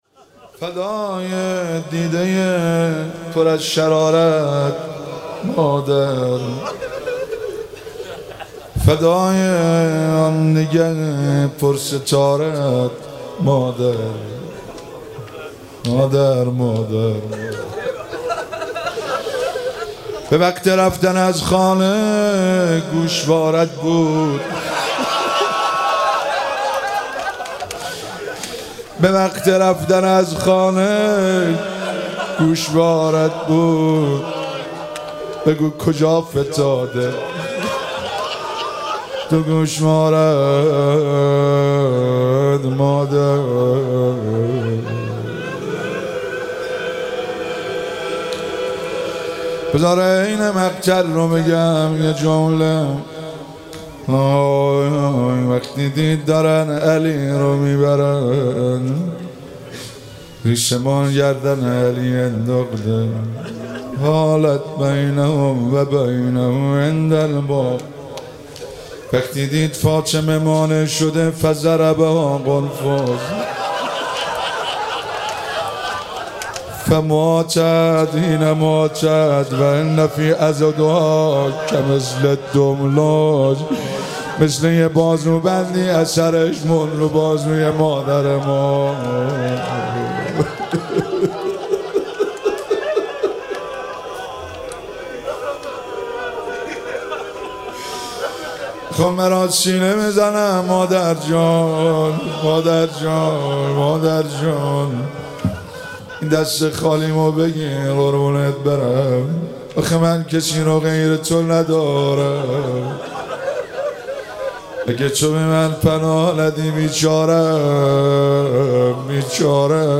روضه - فدای دیده ی پر از شراره ات مادر
مراسم هفتگی25آذر